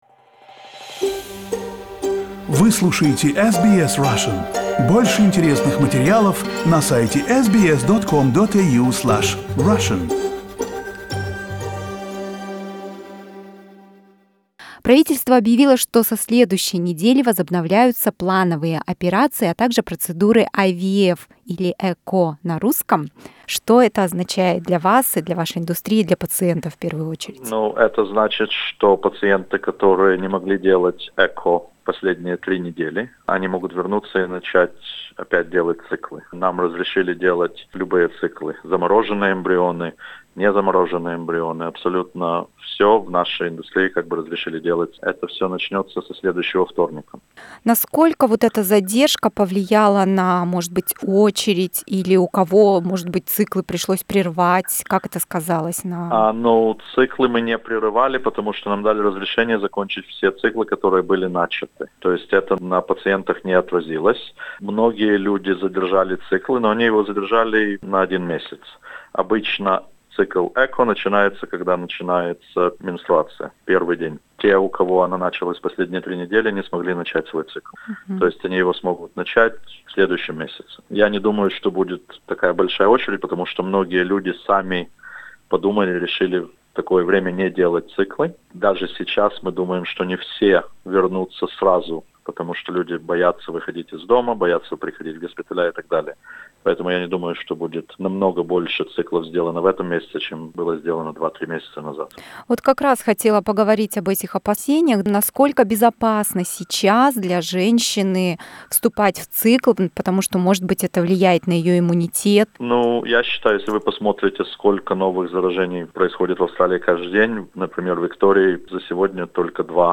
Ключевые моменты интервью